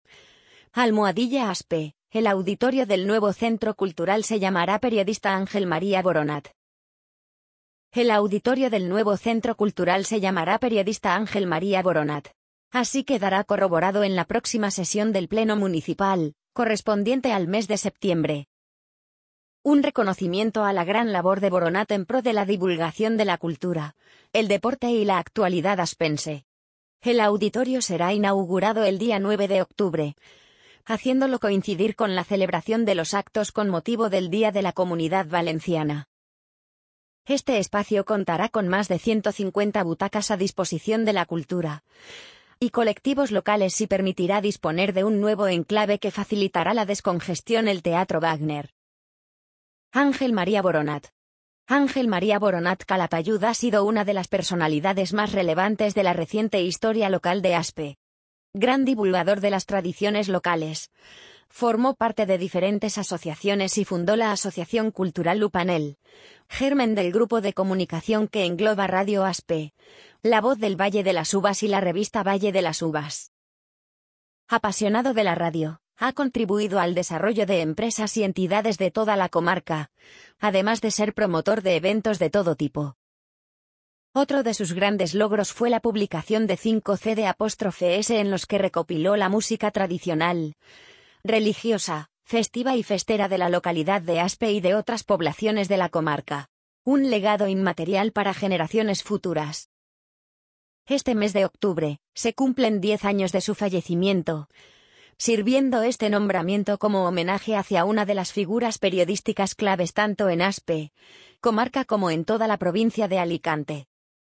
amazon_polly_60375.mp3